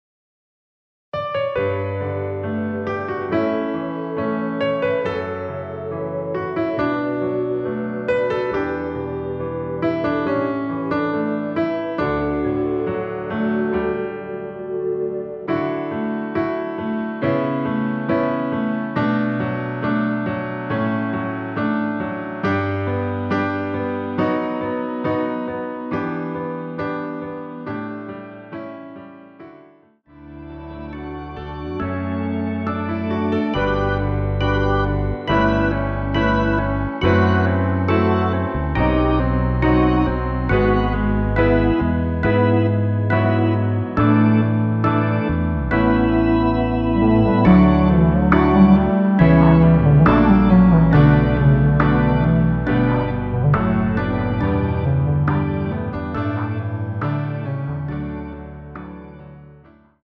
중간 간주 부분이 길어서 짧게 편곡 하였습니다.(미리듣기 확인)
원키 간주 짧게 편곡한 MR입니다.
앞부분30초, 뒷부분30초씩 편집해서 올려 드리고 있습니다.
중간에 음이 끈어지고 다시 나오는 이유는